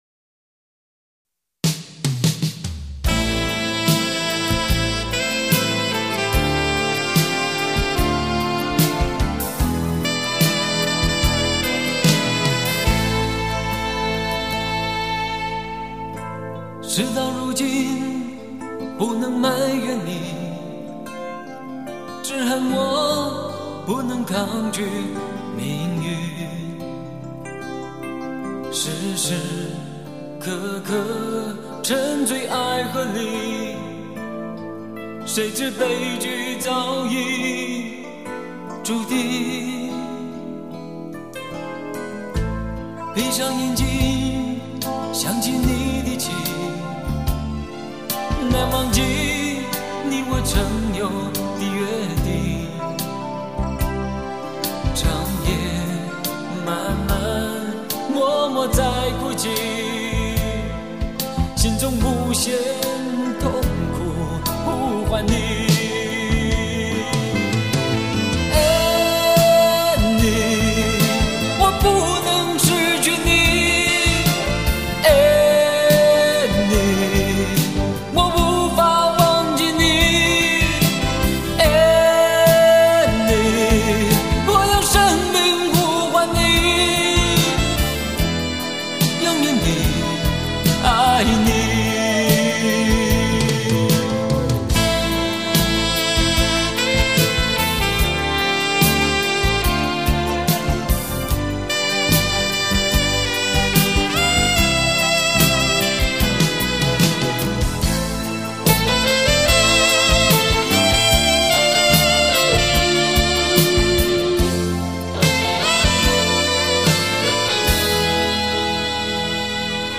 以饱含深情的歌声震撼了歌坛。
他的歌声，让人体会到人生的辛酸和艰难，并充满奋斗的勇气。
伤感的一首